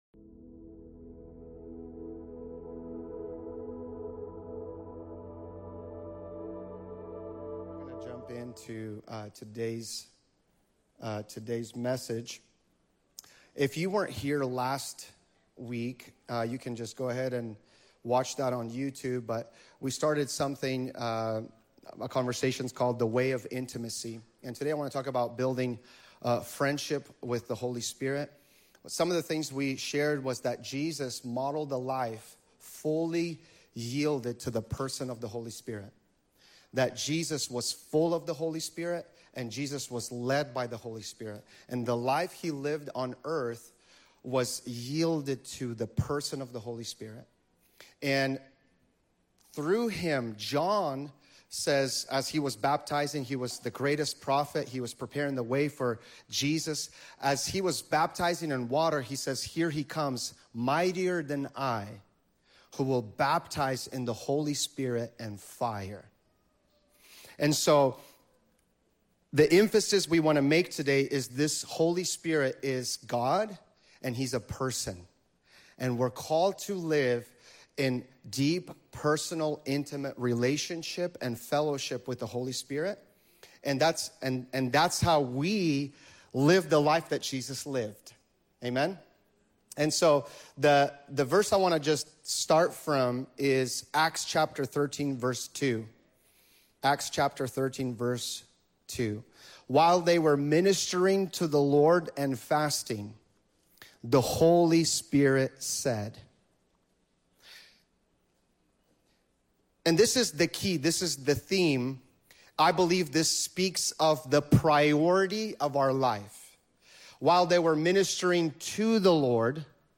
A message on cultivating intimacy with the Holy Spirit, learning to build with God, walk in purity, and live from His anointing rather than our own strength.